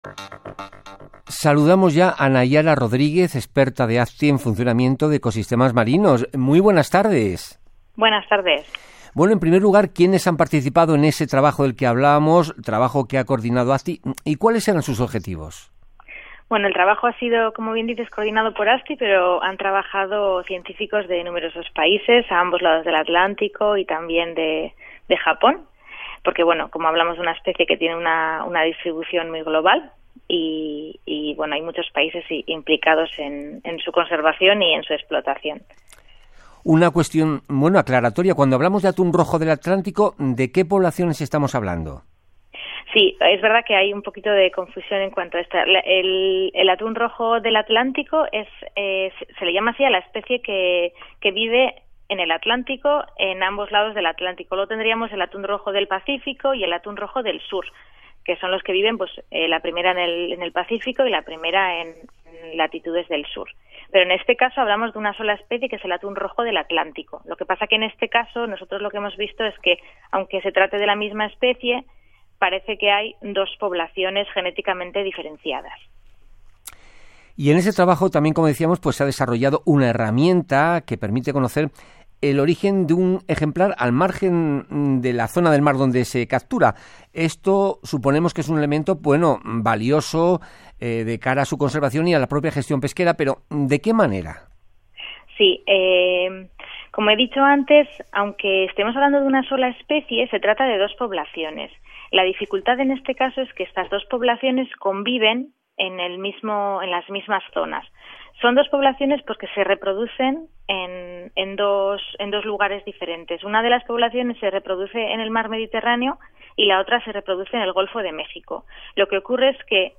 28 August 2019 – Radio Interview (program Españoles en la Mar, Radio Exterior) about tuna genetics (in Spanish)